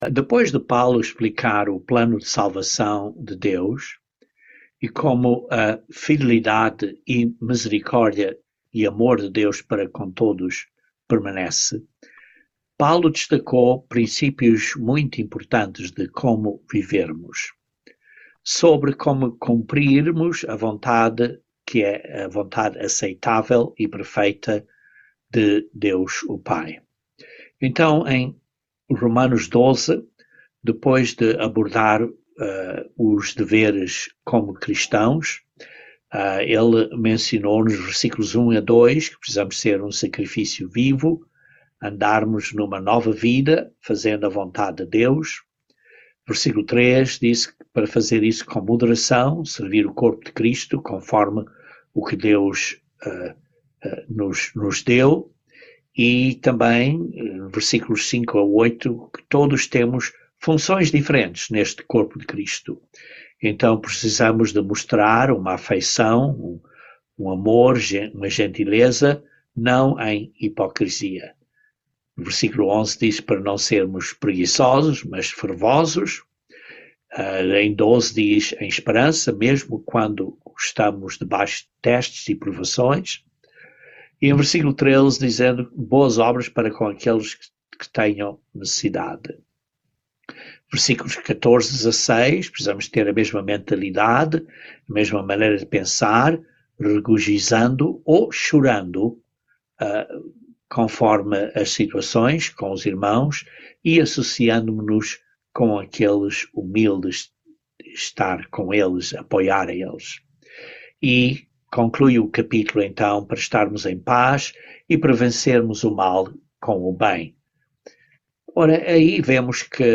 Romanos 13:1-14 - Estudo Bíblico